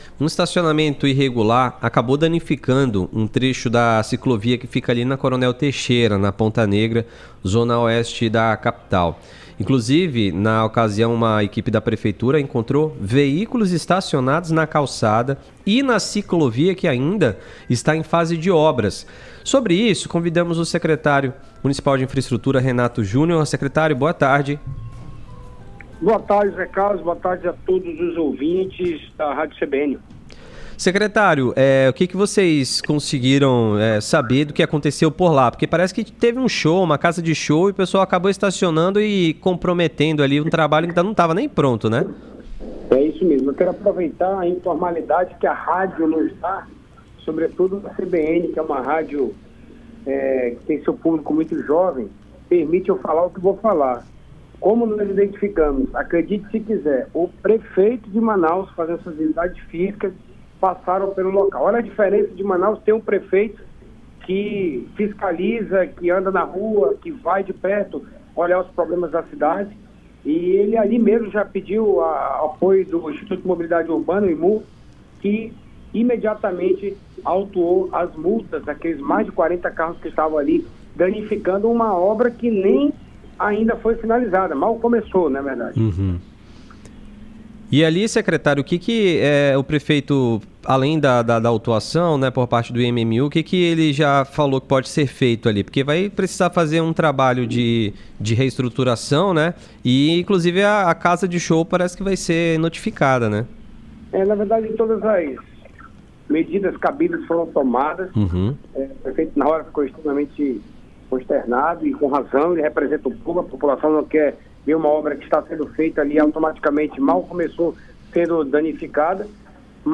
Um estacionamento irregular danificou o trecho de uma ciclovia localizada na Avenida Coronel Teixeira, Ponta Negra, Zona Oeste de Manaus. Em entrevista para o Estação CBN, o secretário municipal de Infraestrutura, Renato Júnior, disse que o prejuízo custou R$ 54 mil.